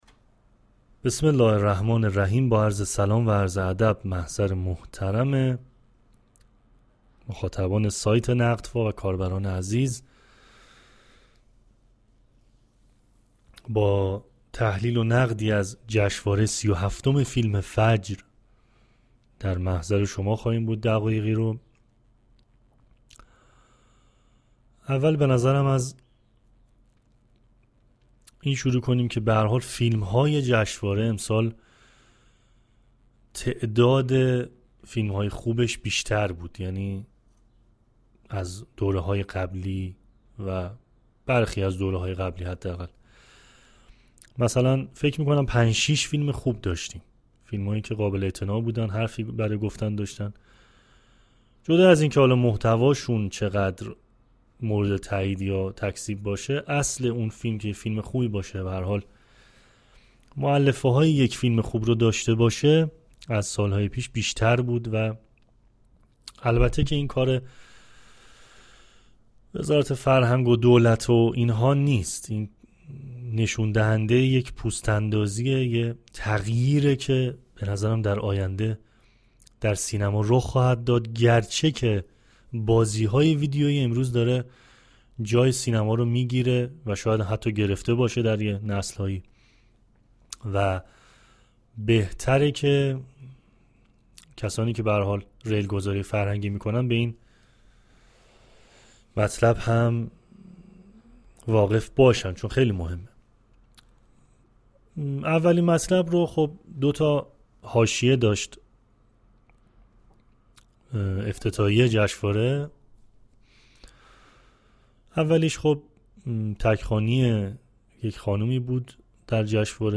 نقد صوتی جشنواره سی و هفتم فیلم فجر و حواشی آن